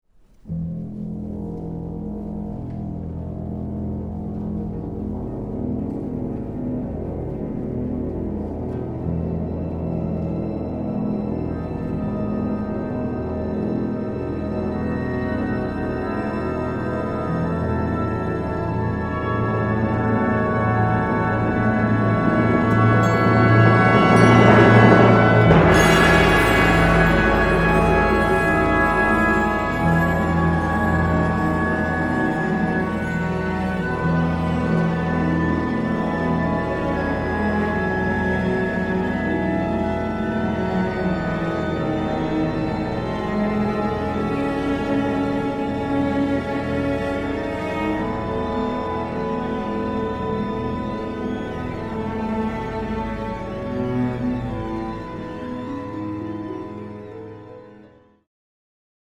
Audio excerpts from the world premiere